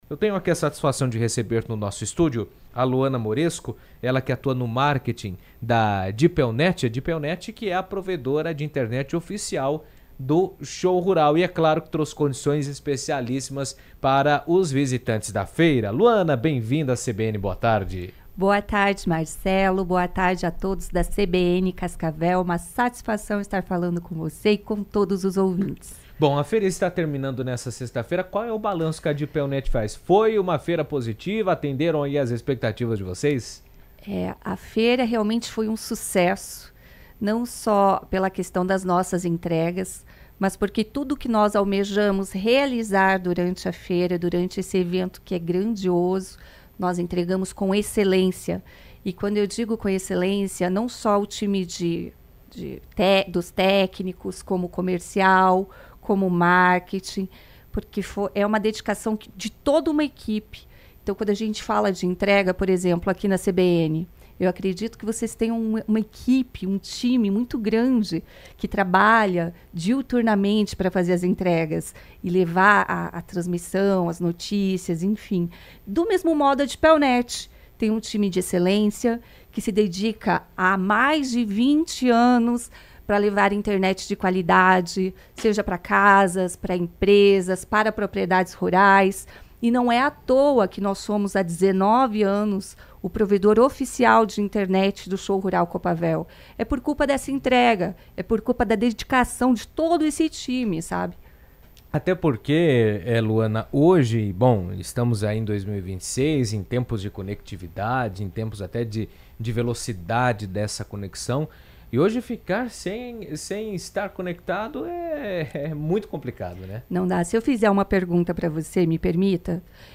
estiveram no estúdio da CBN durante a semana da 38ª edição do Show Rural Coopavel